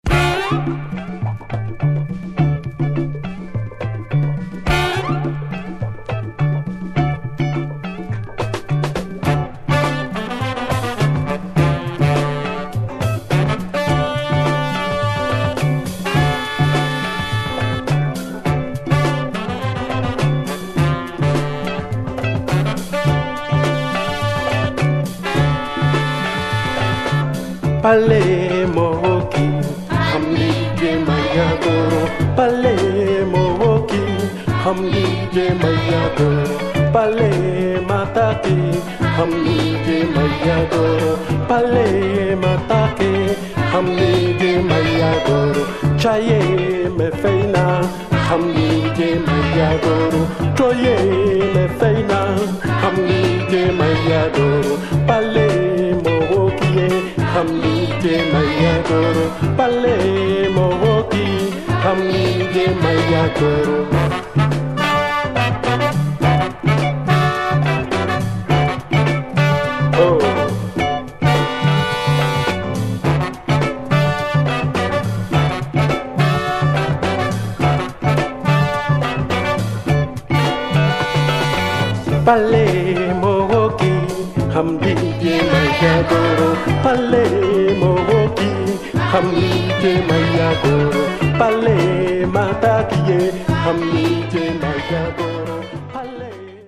Top Afro funk 7'
groove makossa